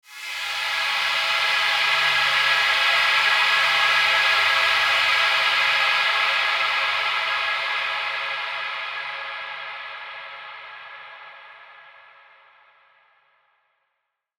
SaS_HiFilterPad08-A.wav